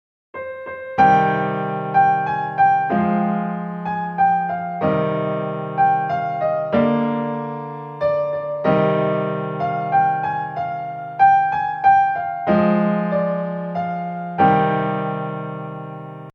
Ahora, si aplicamos el mismo análisis de los ejemplos anteriores y sacamos los acordes y las notas negativas de toda esta pieza, nos encontraremos con lo siguiente:
Esto se debe a que como mencionamos antes, todo acorde mayor lo convertimos en un acorde menor y en cuanto a la melodía, si te fijas, todo movimiento ascendente ahora es descendente y viceversa.